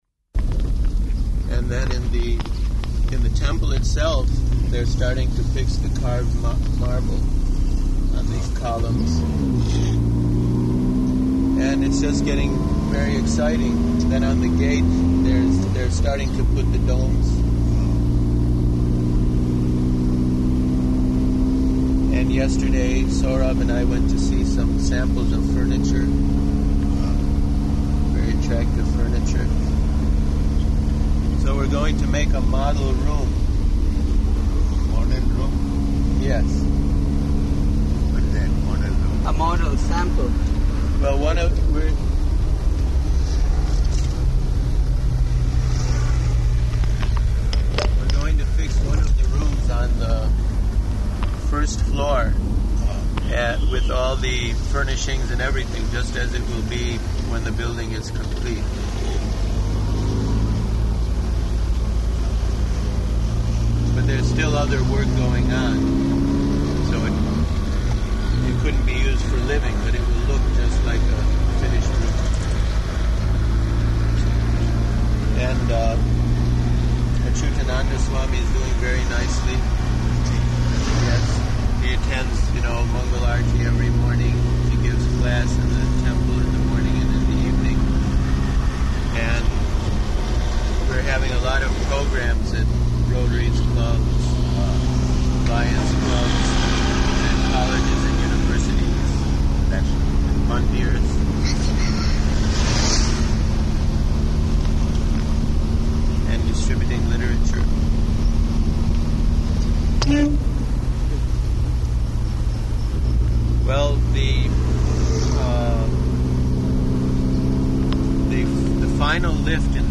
Morning Walk Conversation About Bombay
Type: Walk
Location: Delhi
[in car]